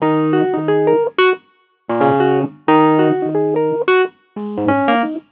bpm90_brokewurl_1_F#.wav